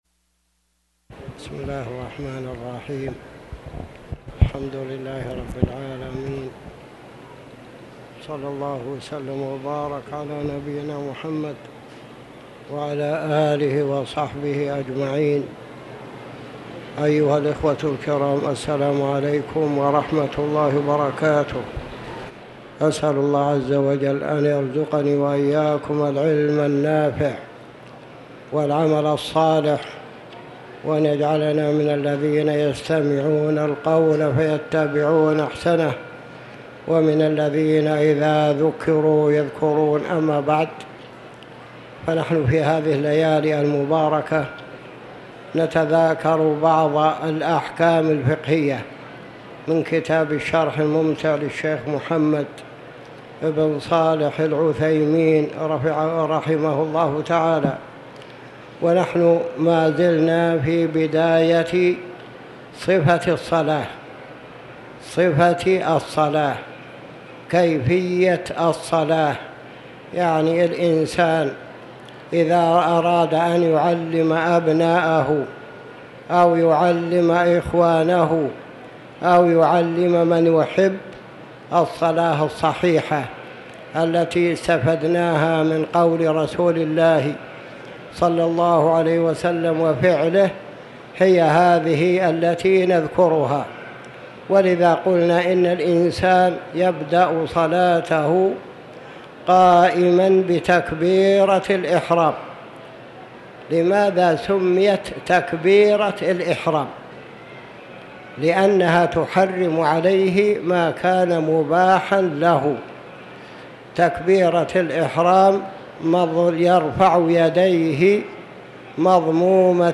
تاريخ النشر ٢٩ شوال ١٤٤٠ هـ المكان: المسجد الحرام الشيخ